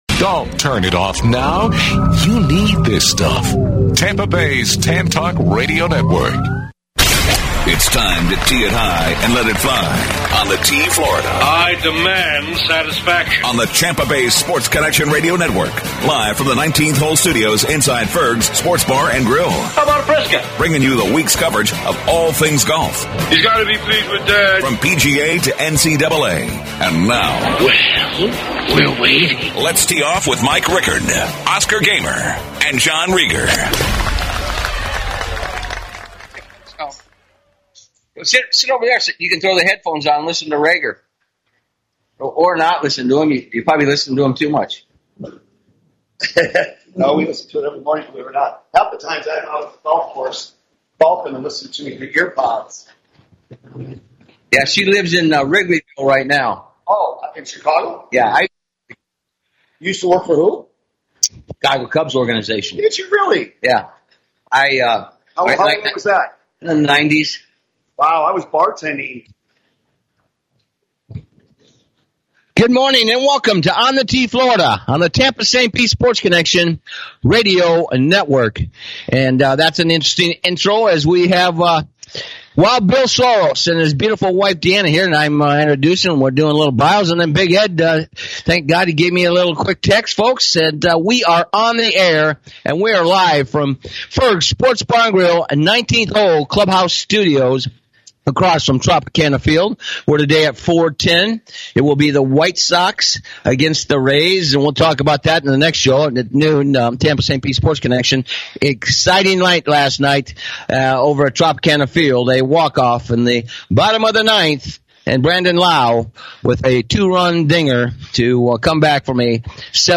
Live from Ferg's